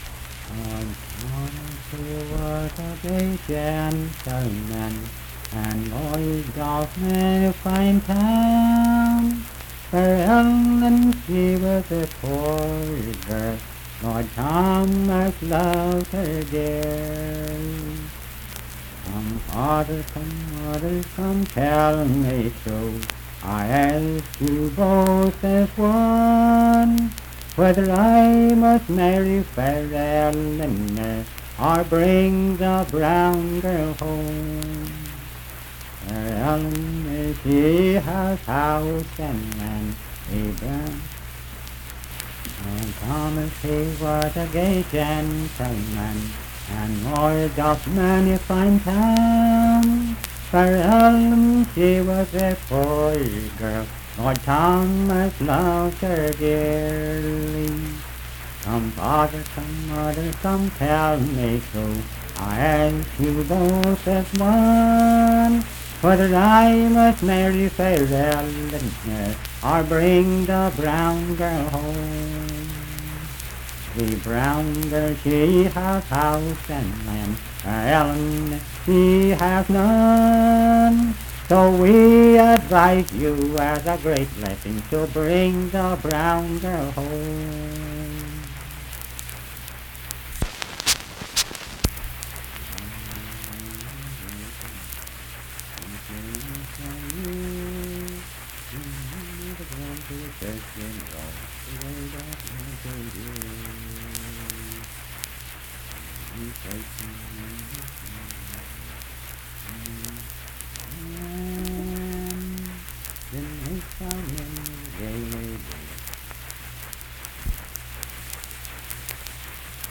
Unaccompanied vocal music
Verse-refrain 3(4).
Performed in Bentree, Clay County, WV.
Voice (sung)